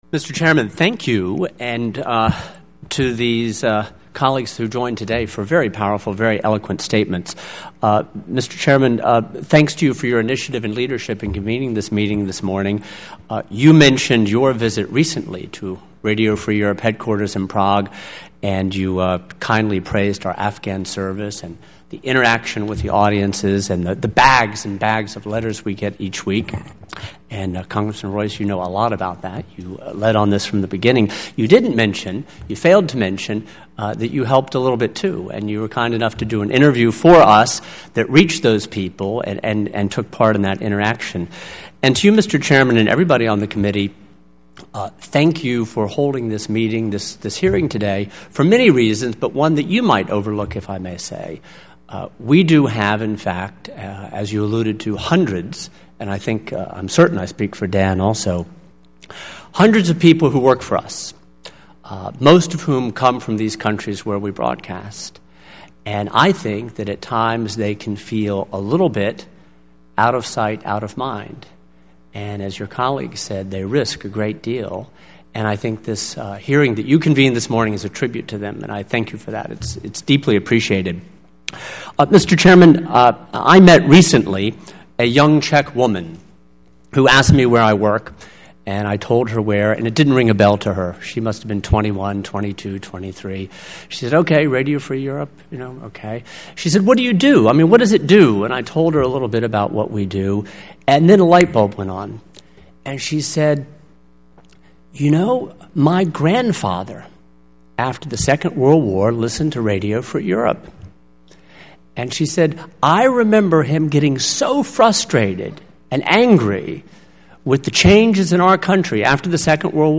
Oral Testimony
Testimony as delivered before the U.S. House Foreign Affairs Committee's Subcommittee on Europe